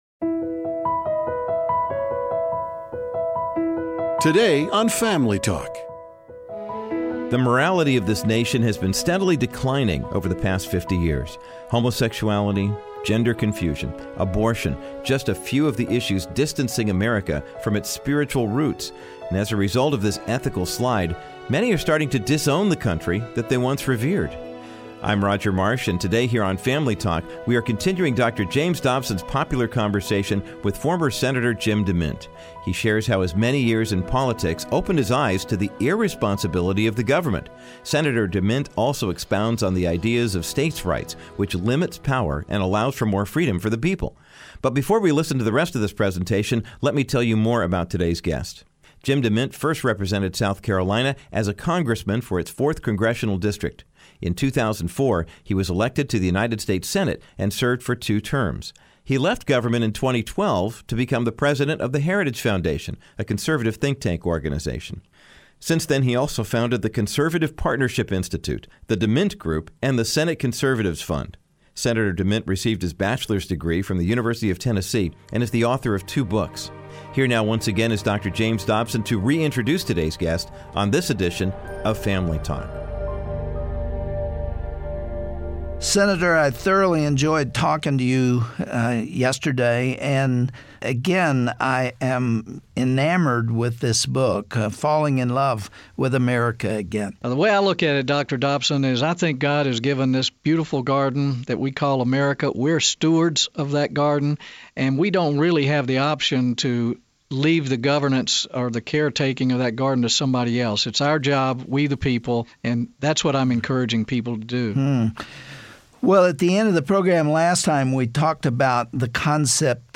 On this Friday edition of Family Talk, Dr. Dobson continues discussing this topic with former senator Jim DeMint. The two highlight the dangers of big government, and explain why returning power to the people will limit corruption, and promote freedom and prosperity.
Host Dr. James Dobson